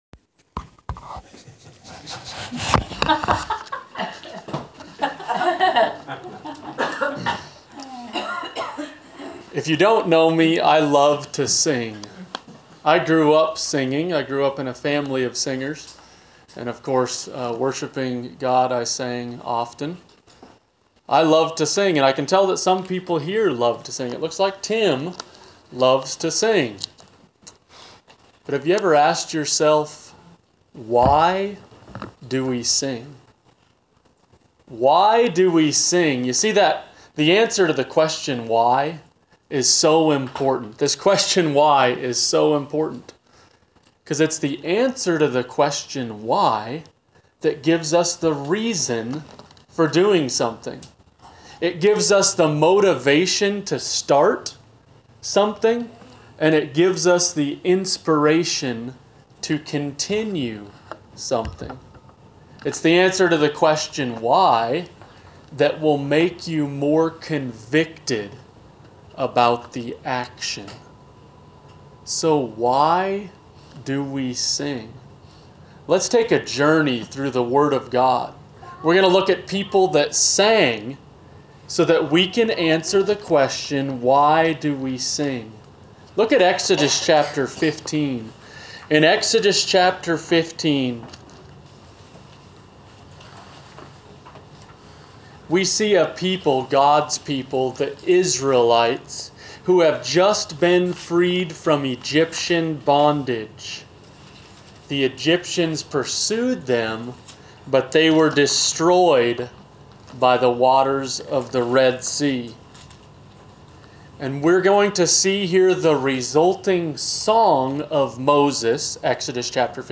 Bible , scripture , sermon , sing , singing Post a comment Cancel Reply You must be logged in to post a comment.